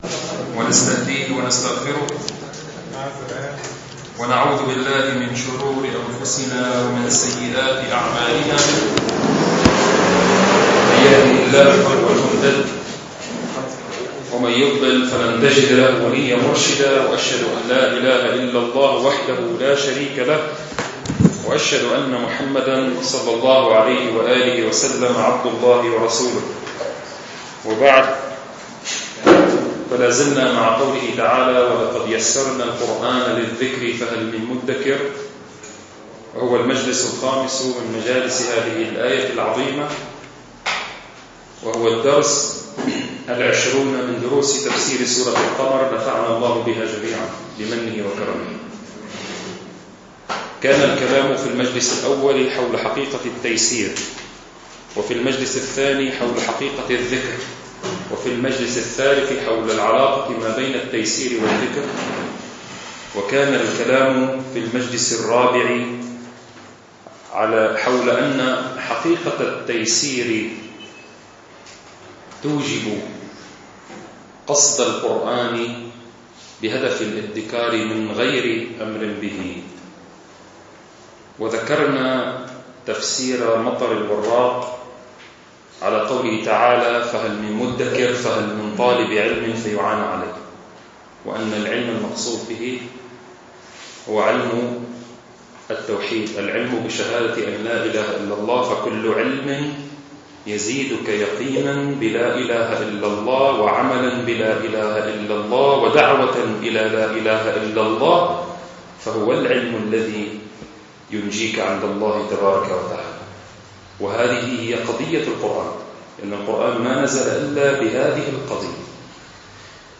المكان : مبنى جماعة عباد الرحمن